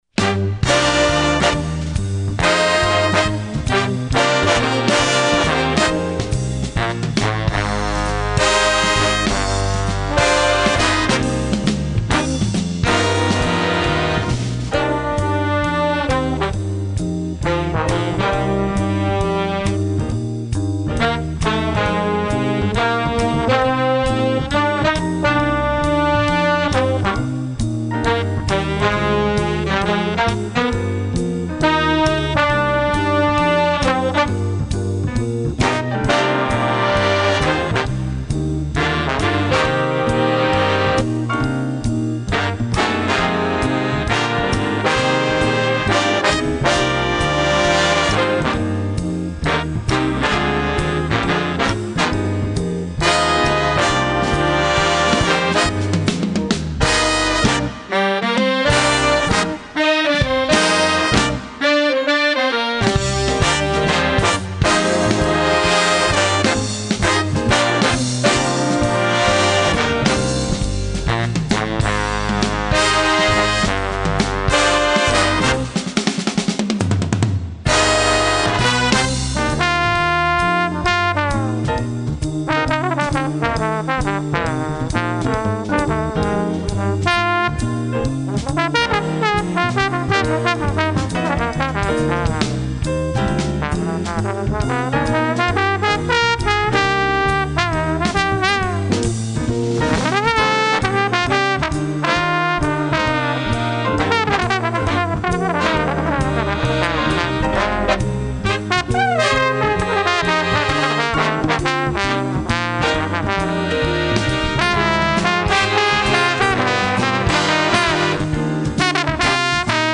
Voicing: playable by 12-17 players